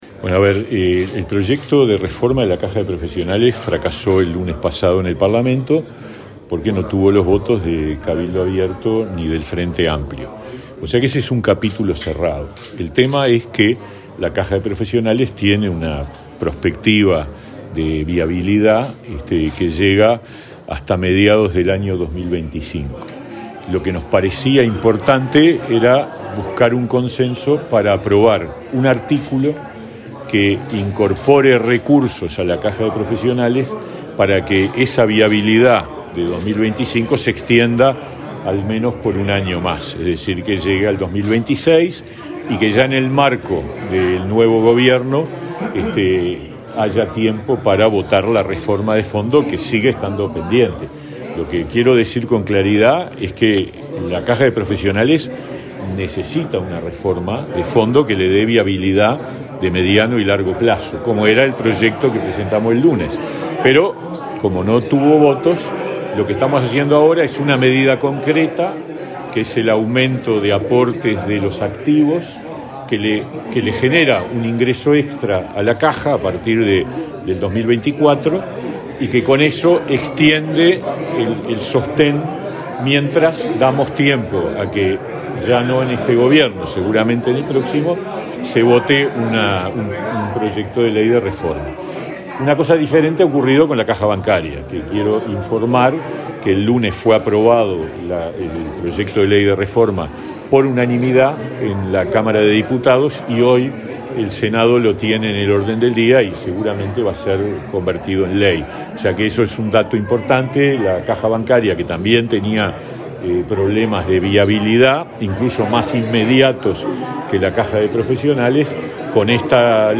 Este miércoles 25, el ministro de Trabajo, Pablo Mieres, dialogó con la prensa, antes de participar en un desayuno de consulta en la Cámara Española.